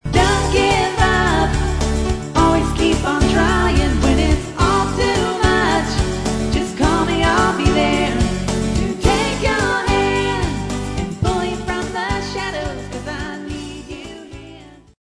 Original Music Samples With Vocals